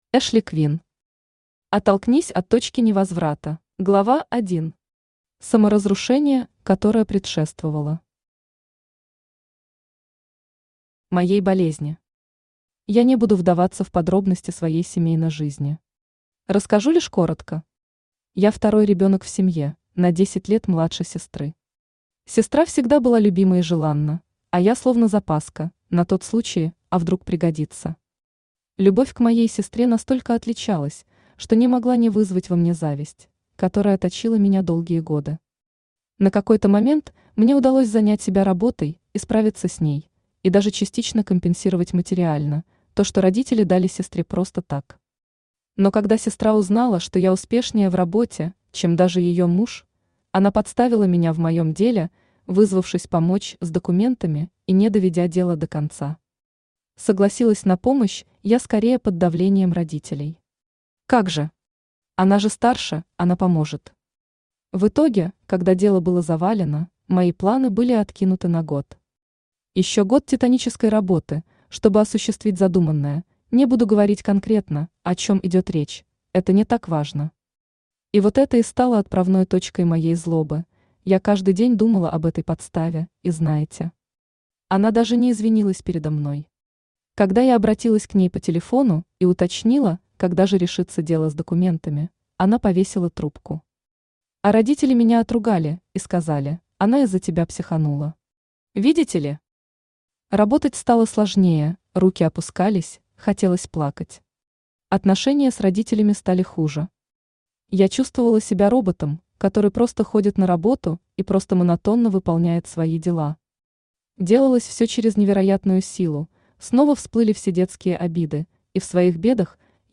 Аудиокнига Оттолкнись от точки невозврата | Библиотека аудиокниг
Aудиокнига Оттолкнись от точки невозврата Автор Эшли Квин Читает аудиокнигу Авточтец ЛитРес.